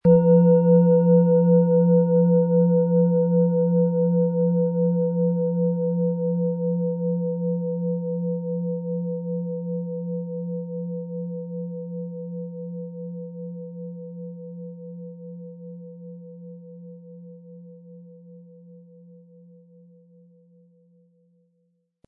Planetenschale® Erkenne Deinen Lebensweg & Spirituelle Führung wahrnehmen mit Platonisches Jahr, Ø 18,6 cm, 800-900 Gramm inkl. Klöppel
Planetenton 1
Ein unpersönlicher Ton.
MaterialBronze